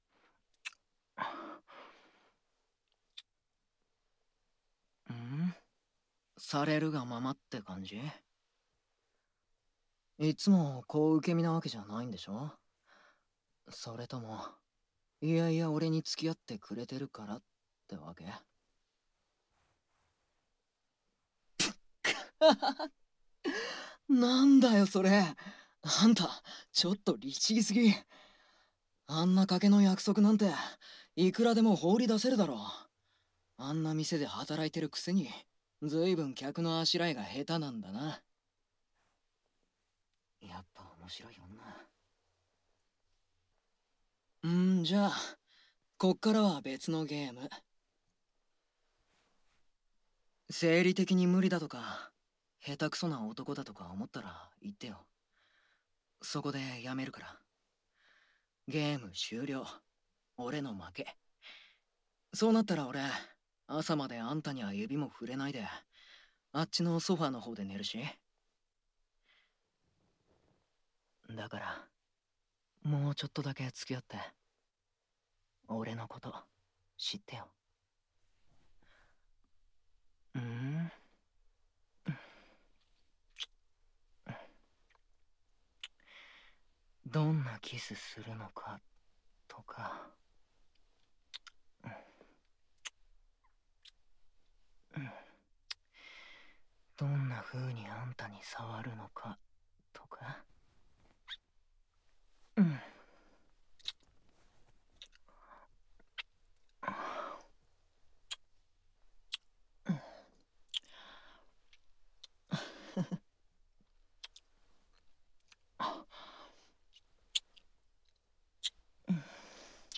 カフェで働く４人の男子の恋模様を描く、オムニバスドラマＣＤ！
【本編サンプルボイス】